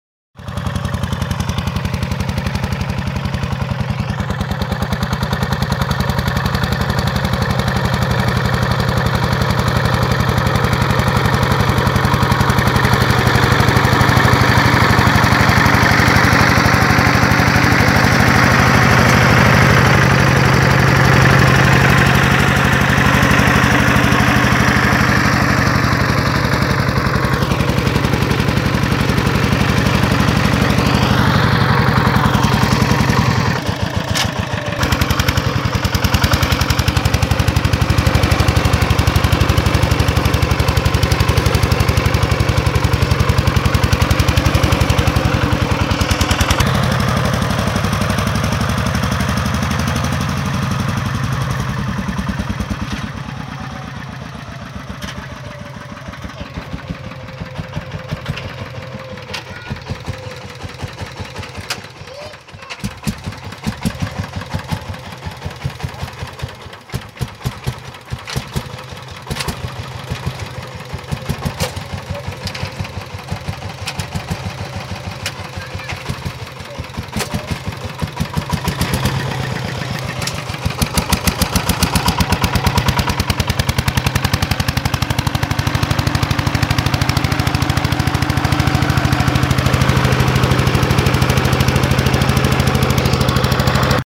Звуки мотоблока
Звук дизельного мотоблока Зубр